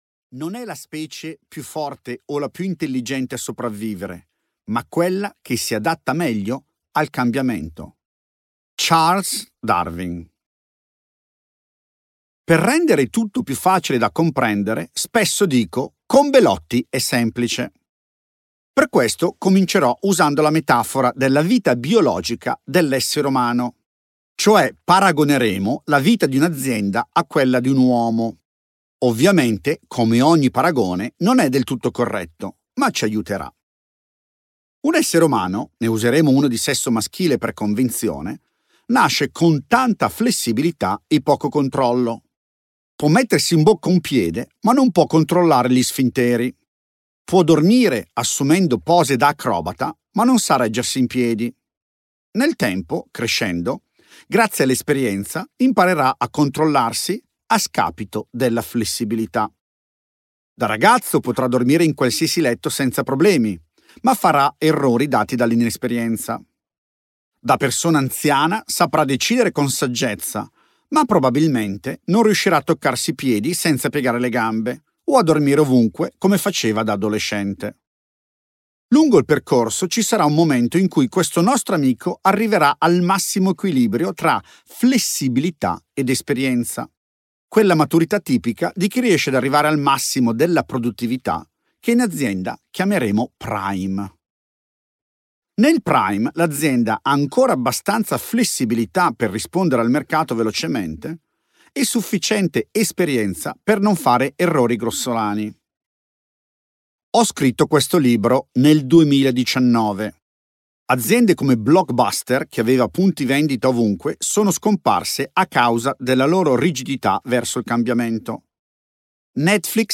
Gli Stadi del successo – Capire i Cicli Aziendali per Mantenere la tua Impresa al Picco della Prestazione è un audiobook scritto e letto da me.